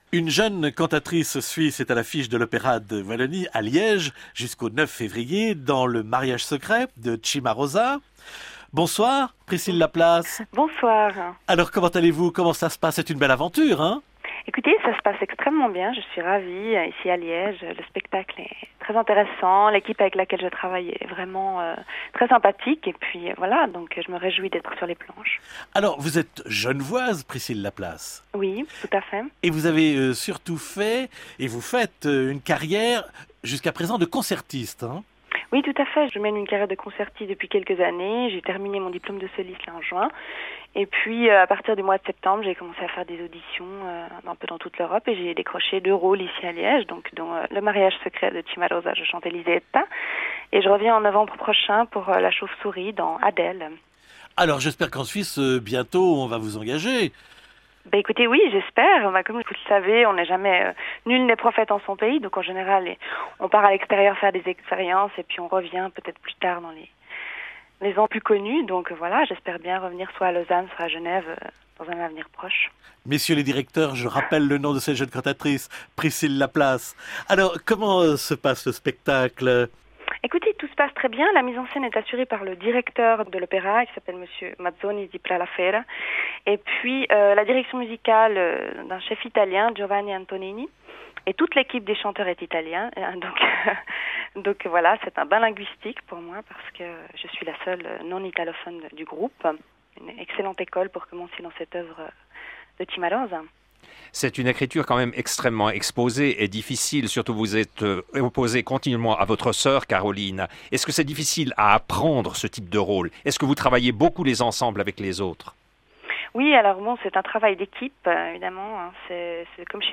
icone_pdf Avant-Scène, interview du 2 février 2008 au sujet de "Il Matrimonio Segreto" de Cimarosa
interview-cimarosa.mp3